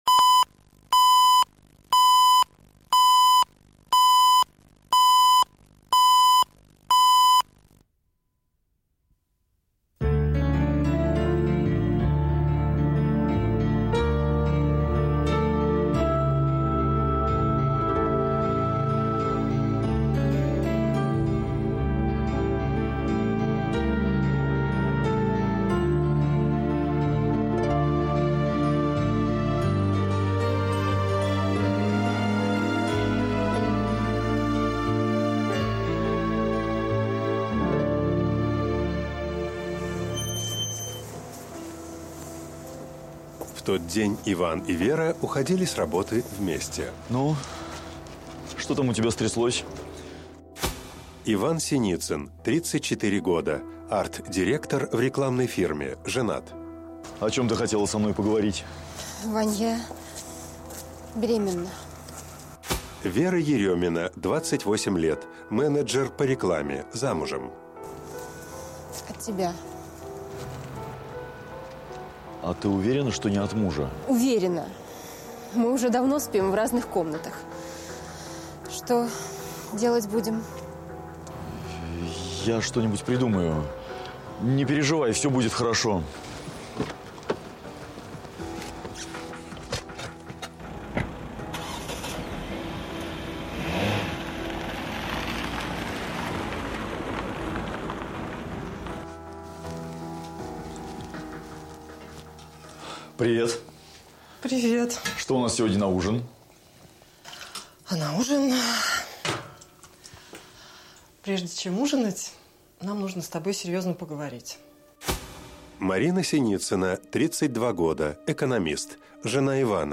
Аудиокнига Я жду ребенка | Библиотека аудиокниг